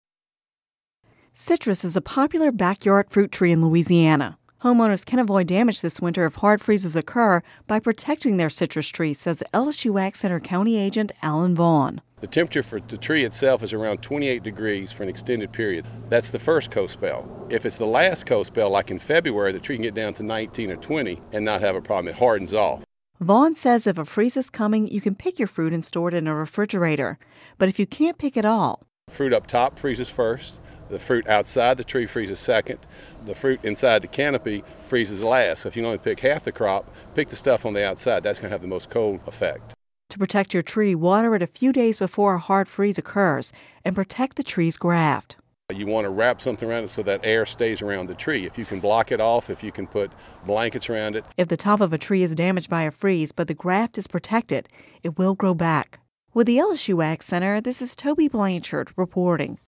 (Radio News 12/20/10) Citrus is a popular backyard fruit tree in Louisiana.